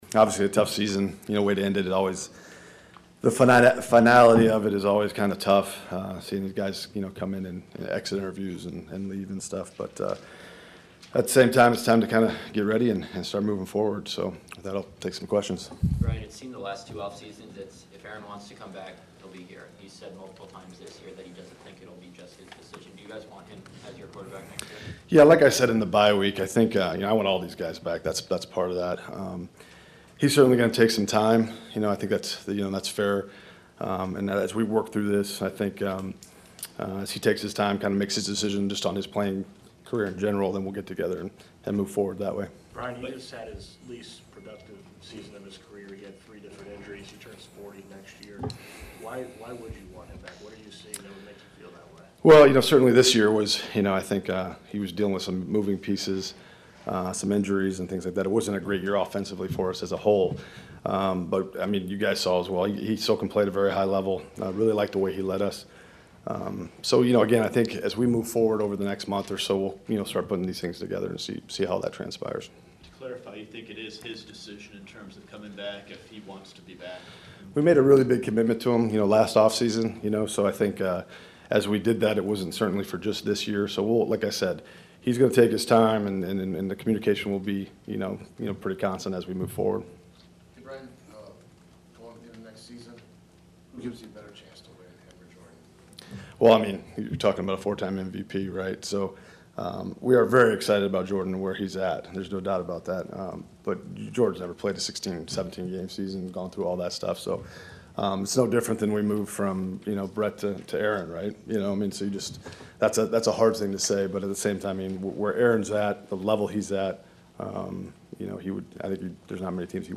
The GM arrived in the Lambeau Field media auditorium for his season ending news conference just after 10:00 AM to explain what led to the disappointing 8-9 season, with a 3-1 start, a five game losing streak, a December resurgence and playoff destiny slipping out their own hands last Sunday when the Detroit Lions denied them the NFC’s final Wild Card.